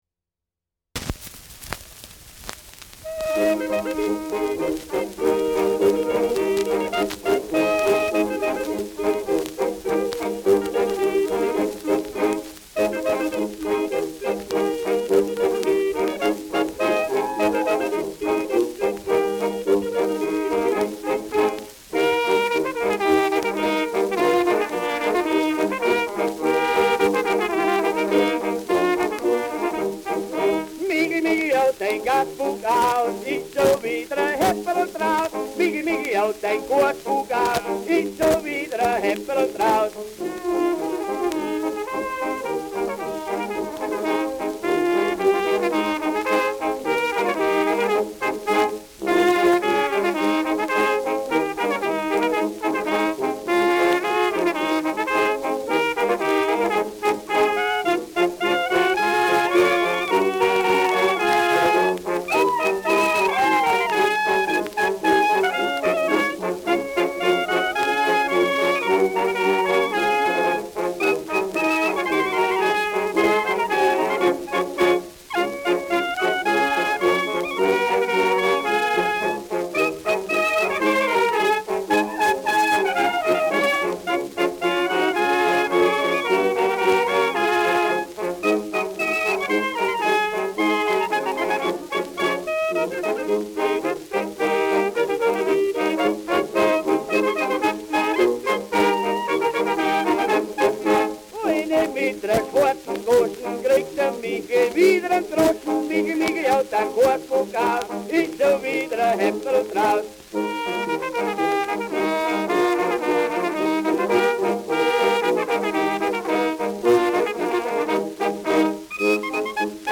Schellackplatte
Leichtes Leiern